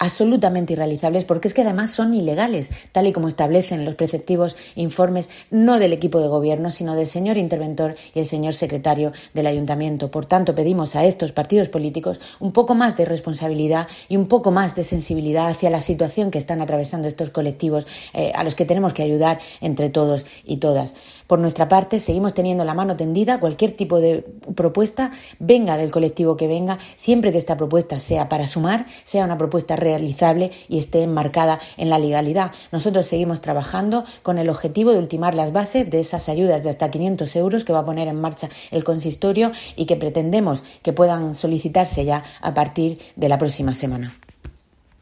Isabel Casalduero, portavoz del PSOE